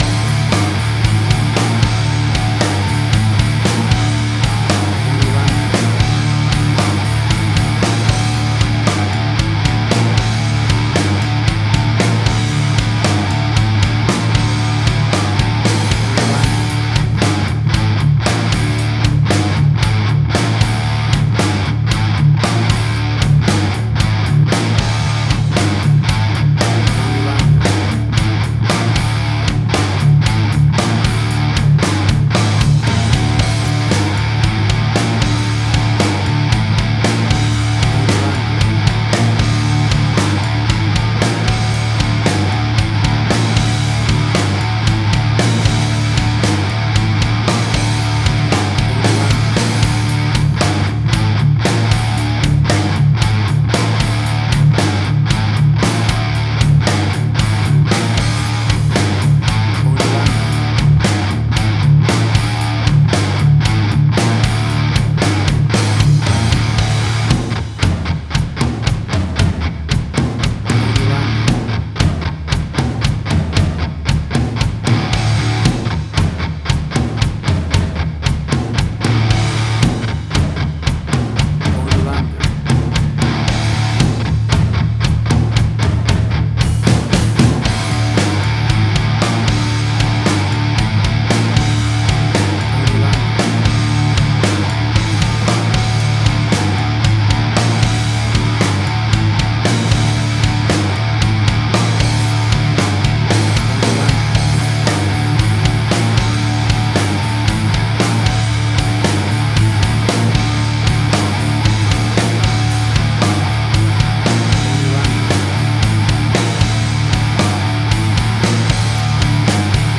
Hard Rock, Similar Black Sabbath, AC-DC, Heavy Metal.
Tempo (BPM): 115